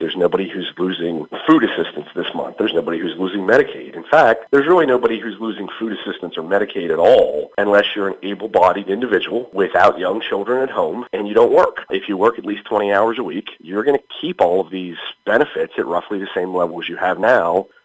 Maryland’s House Minority Leader tells WCBC that he does not expect significant fiscal impacts on Maryland in the short-term from the DC Mega-bill.  Jason Buckel told WCBC most changes in the legislation do not come into law until between 2026 and 2028.